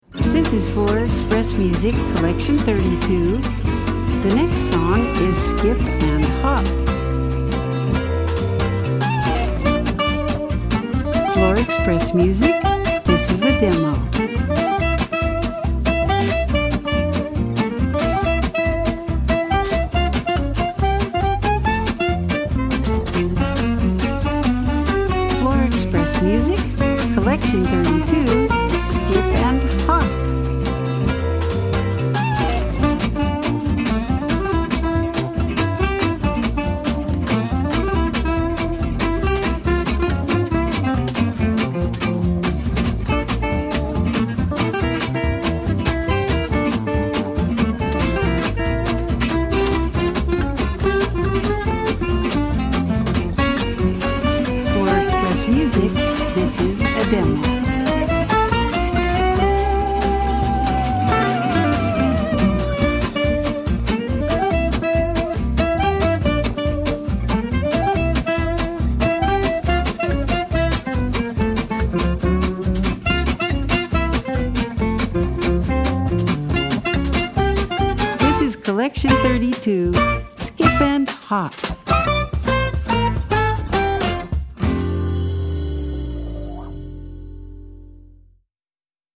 1:27 Style: Jazz Category